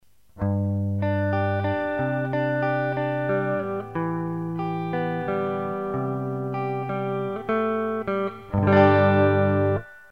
cleanreverbtube2.mp3